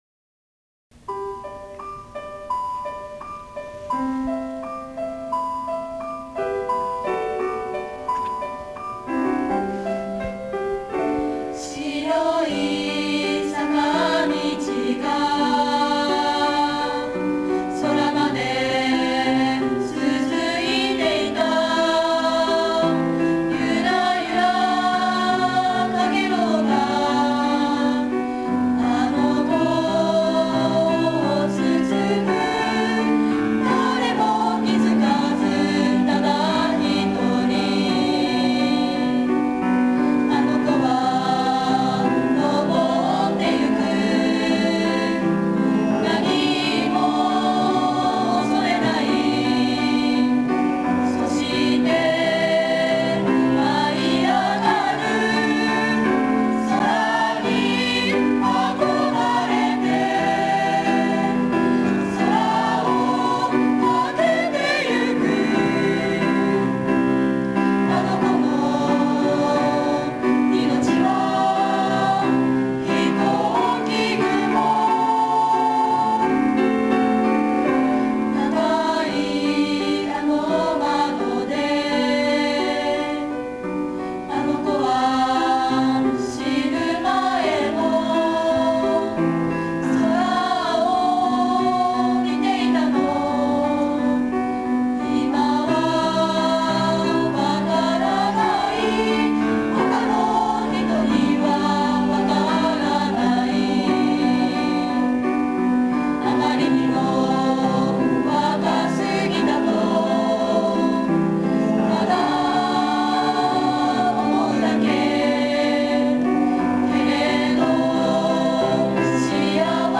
～校内合唱コンクールが行われました～
第2学期の主要な行事の一つである校内合唱コンクールが11月11日（火）午前中に，本学院メインアリーナを会場に開催されました。